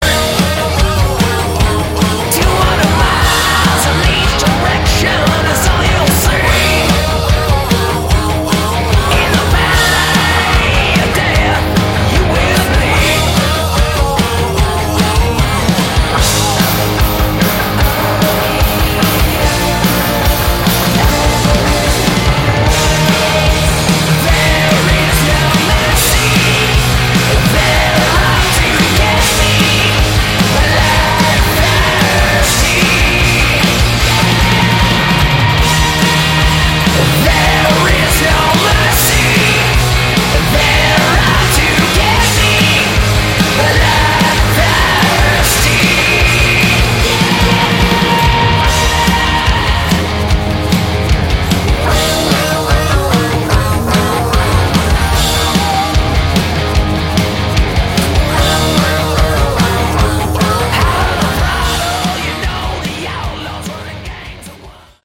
Category: Sleazy Hard Rock
vocals
guitar
drums
bass